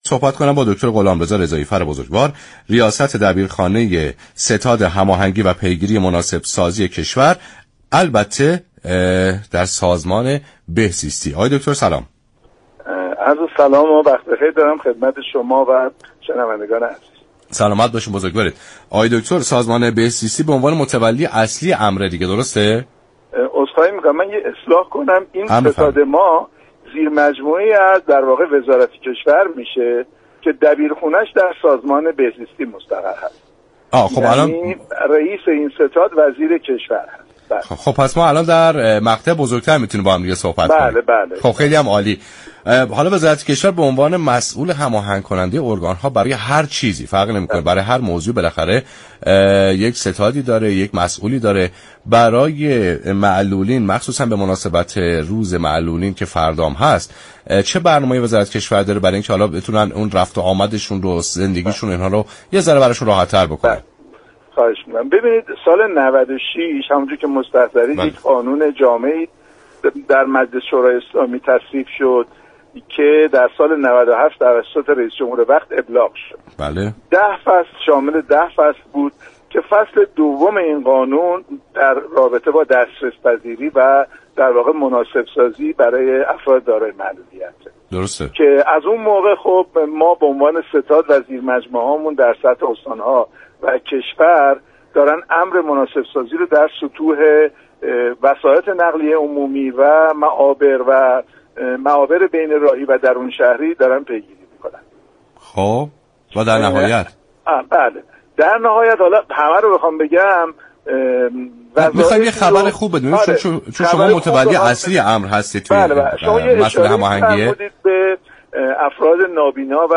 به گزارش فضای مجازی رادیویی ایران، غلامرضا رضایی‌فر رییس دبیرخانه ستاد هماهنگی پیگیری مناسب‌سازی‌كشور در برنامه ایران امروز در پاسخ به اینكه دولت برای بهبود وضعیت معلولین در كشور چه اقداماتی را انجام داده است،‌افزود:مجلس در سال 96 قانون جامعی را وضع‌كرد كه سال 97 توسط رییس جمهور‌وقت به دستگاه‌ها ابلاغ‌گردید.